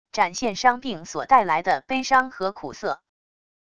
展现伤病所带来的悲伤和苦涩wav音频